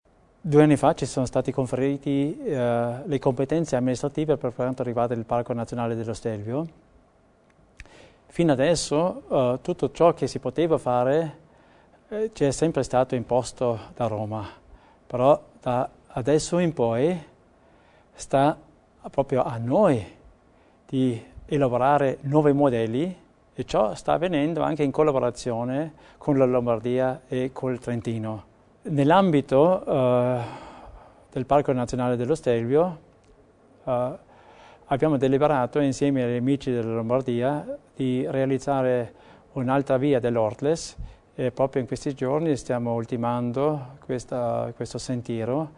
Nell’ambito della serie tematica #5 oggi, 30 luglio, a Palazzo Widmann a Bolzano, l’assessore Theiner ha stilato un breve bilancio dell’attività svolta in questi cinque anni illustrando le sfide affrontate e i traguardi raggiunti nell’arco del suo mandato.